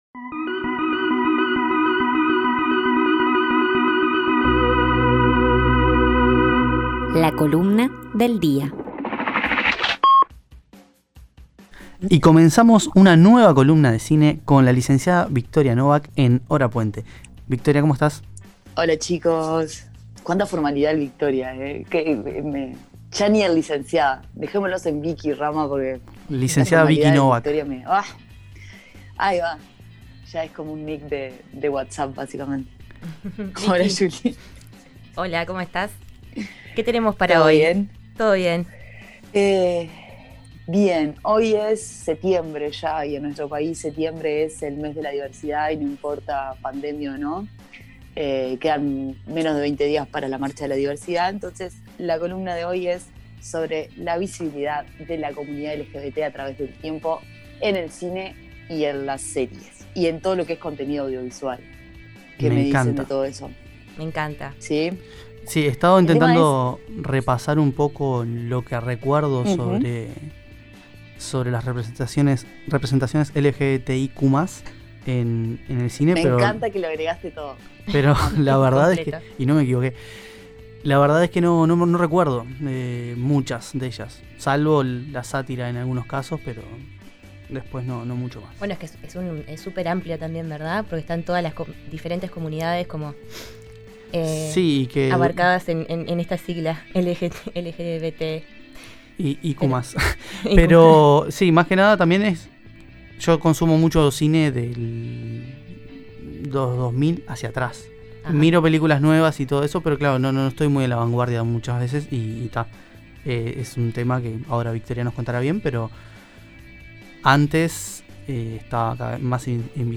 En nuestra entrevista de hoy, conversamos sobre “Enseña Uruguay” la asociación civil que trabaja con estudiantes de liceos en contextos vulnerables, con el fin de brindar una educación de calidad y reducir brechas.